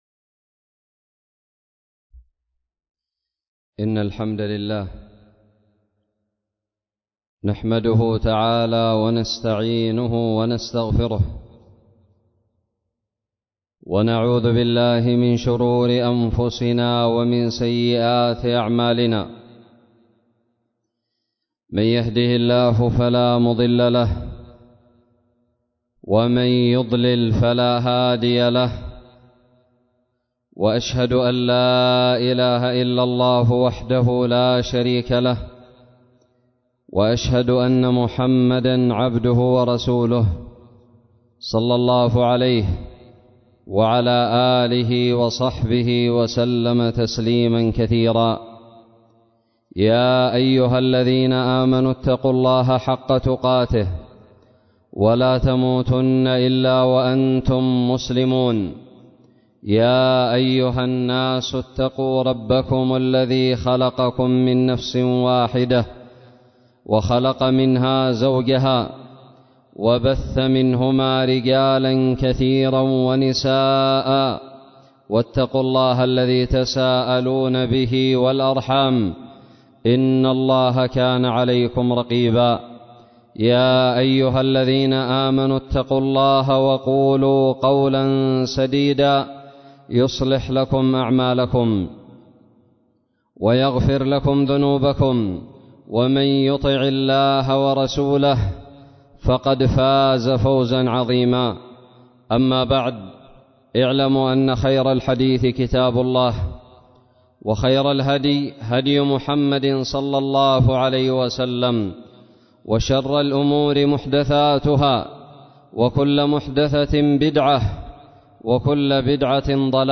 خطب الجمعة
ألقيت بدار الحديث السلفية للعلوم الشرعية بالضالع في 9 شوال 1442هــ